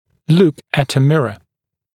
[luk ət ə ‘mɪrə][лук эт э ‘мирэ]смотреть в зеркало